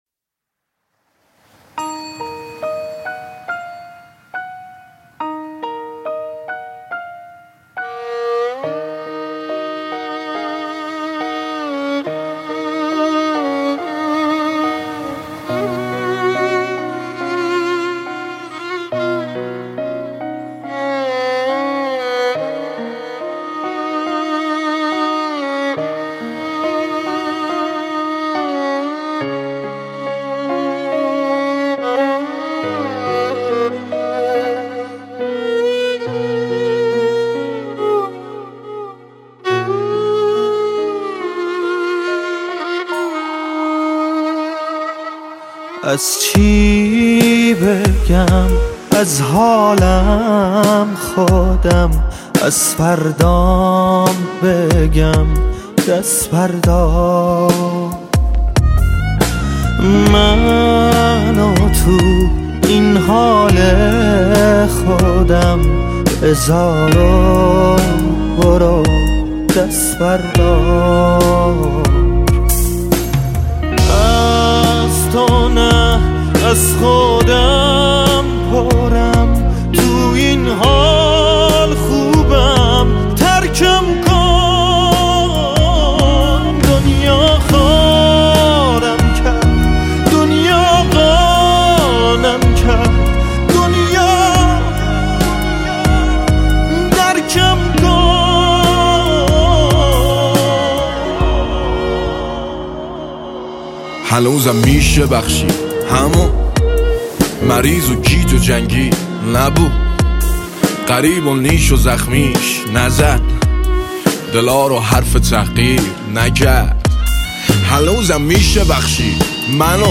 ویلون
گیتار و گیتارباس